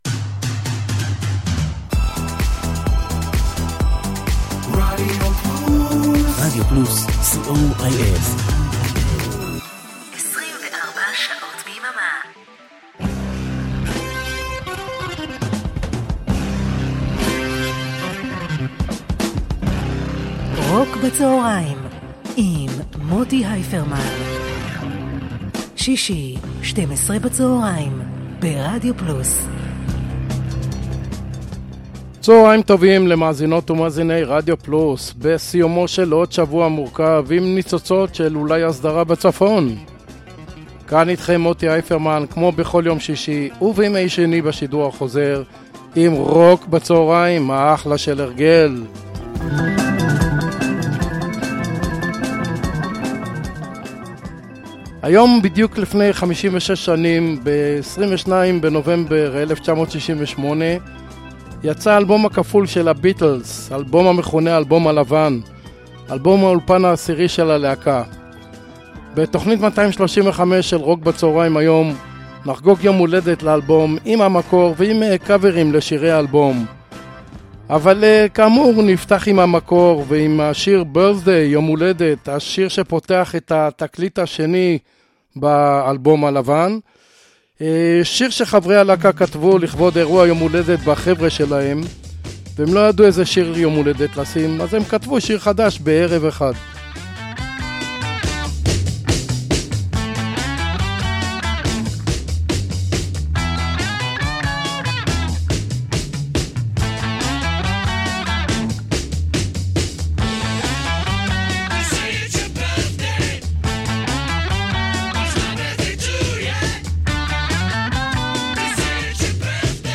blues rock classic rock